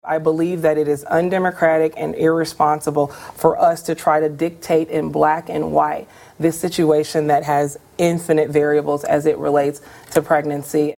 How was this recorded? abortion and carbon pipelines during their debate last night on Iowa PBS.